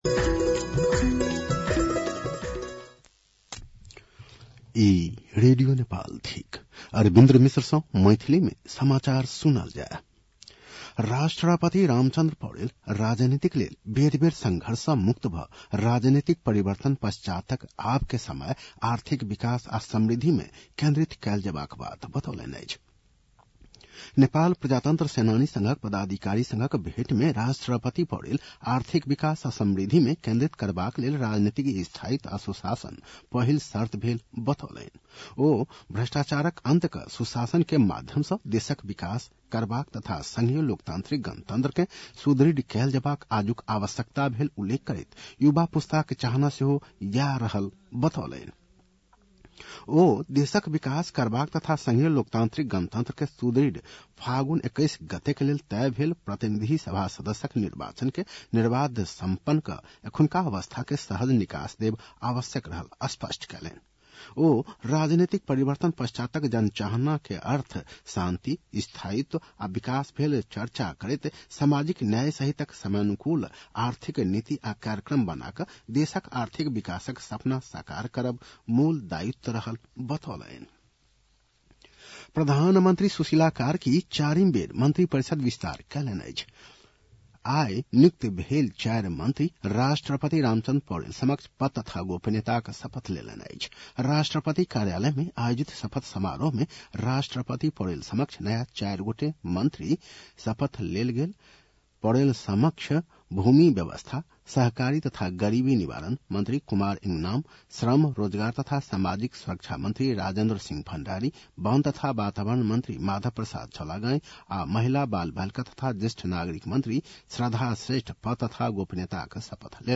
मैथिली भाषामा समाचार : २६ मंसिर , २०८२
Maithali-news-8-26.mp3